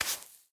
brushing_sand2.ogg